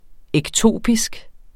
Udtale [ εgˈtoˀpisg ]